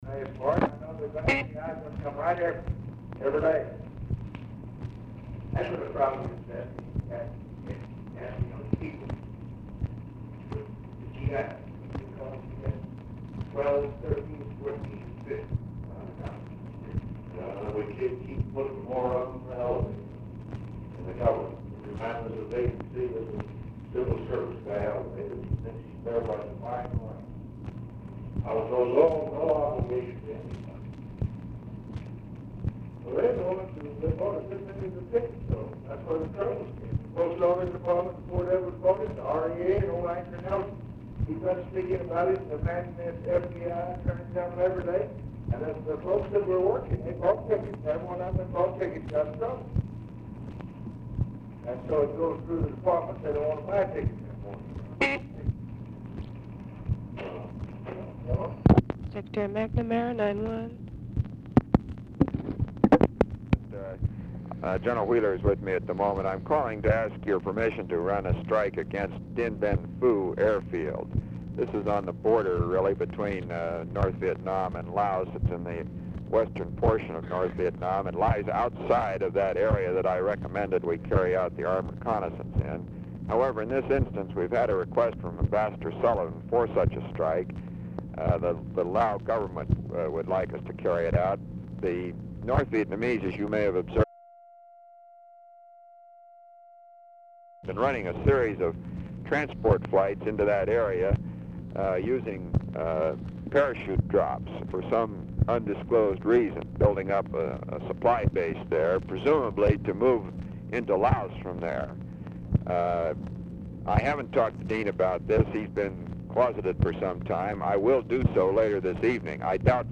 Telephone conversation # 9611, sound recording, LBJ and ROBERT MCNAMARA, 2/2/1966, 7:10PM
ALMOST INAUDIBLE OFFICE CONVERSATION PRECEDES CALL
Format Dictation belt